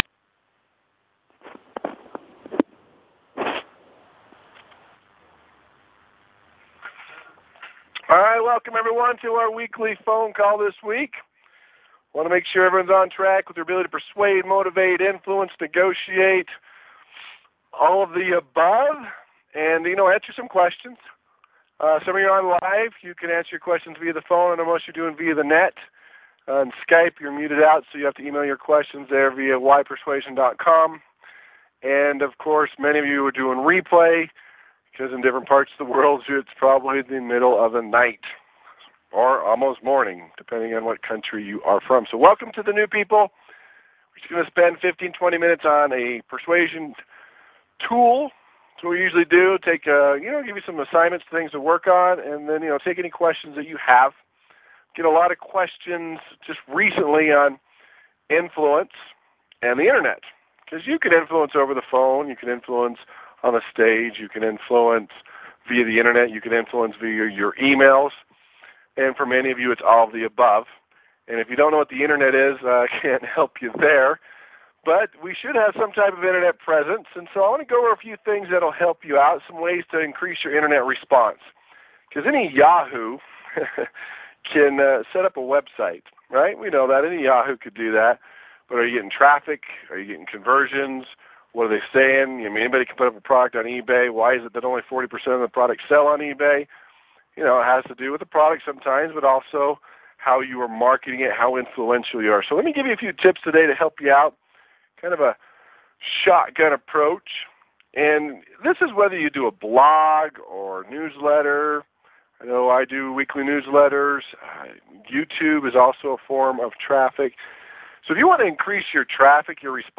Conference Calls